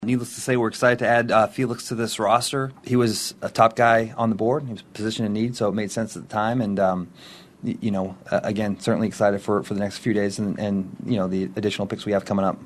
Chiefs GM Brett Veach says he fits a need.